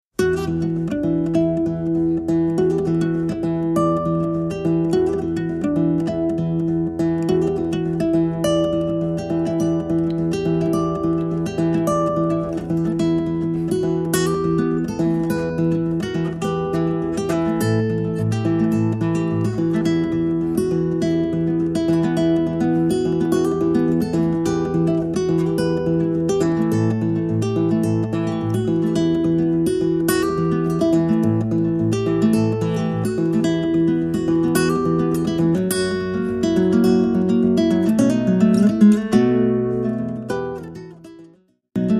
--folk-bluegrass music